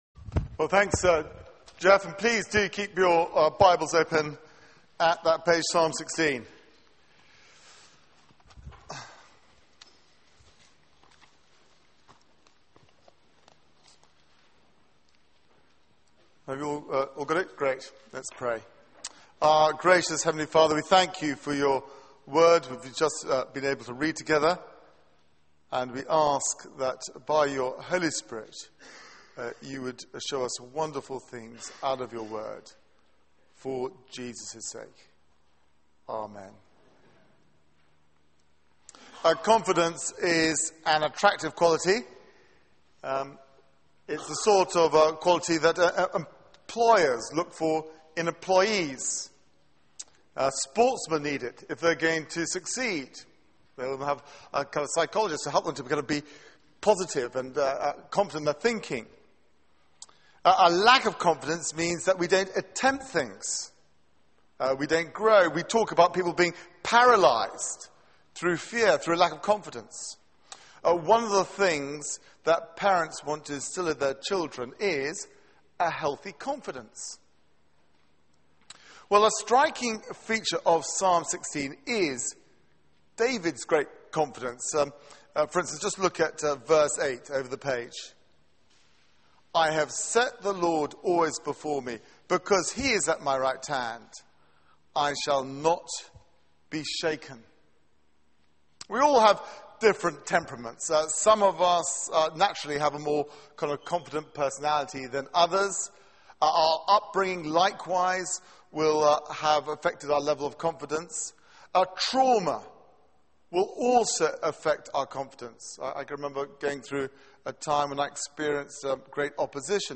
Media for 6:30pm Service on Sun 26th Aug 2012 18:30 Speaker
Psalm 16 Series: Summer Songs Theme: Joy everlasting Sermon Search the media library There are recordings here going back several years.